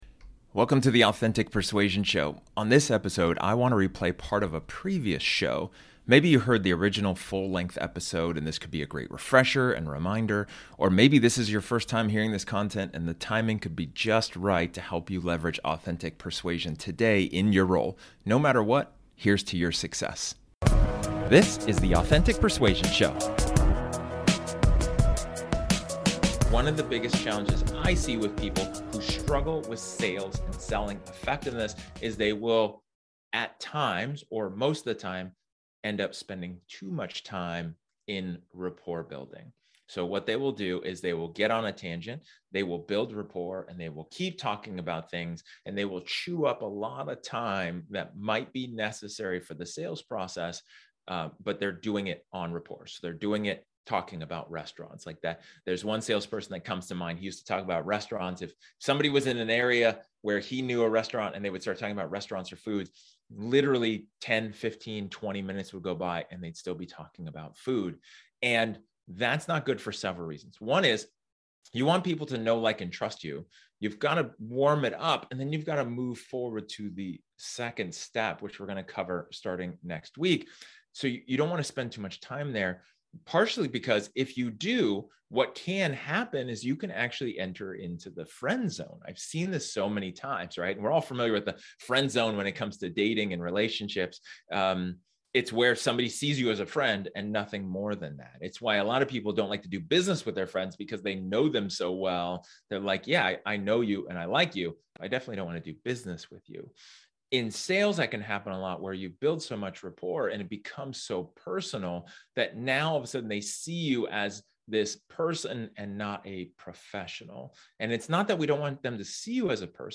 This episode is an excerpt from one of my training sessions where I talk about building rapport.